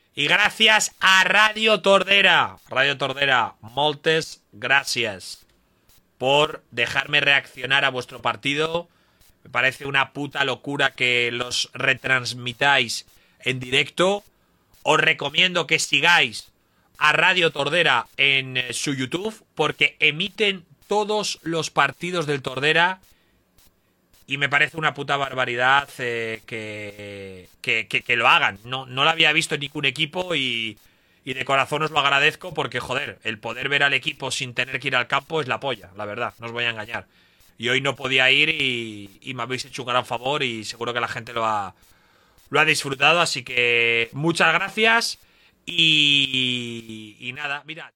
Retransmissió del partit amistós de pretemporada entre el Club de Fútbol Tordera i el Rònin Futbol Club, propietat d'Ibai Llanos, disputat al camp municipal d'esports de Tordera.
Esportiu